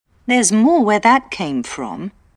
◆ ＜リスニング＞more と where　英検準１級パート１過去問から抜粋
使われている単語は、全て1音節です。
ゼz モー ウェ ザッ ケm fロm
全体で6音節です。
theres-more-where-that-came-from_BE.m4a